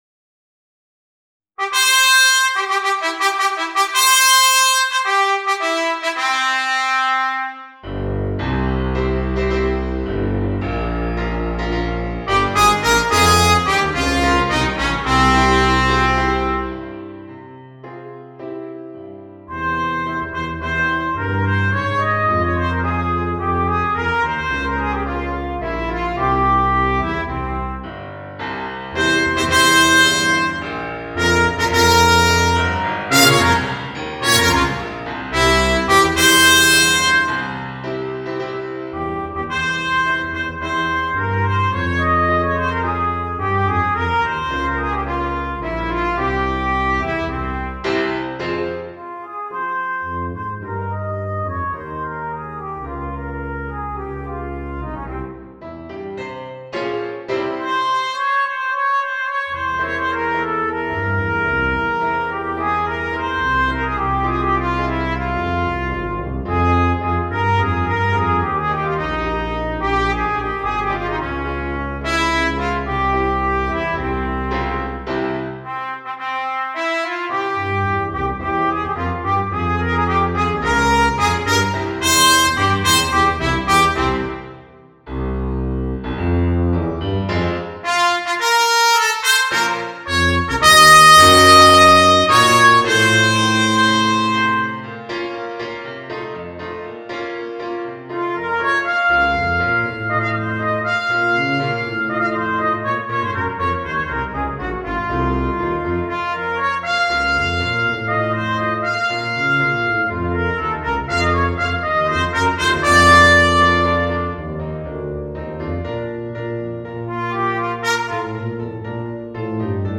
（ピッコロ+ピアノ）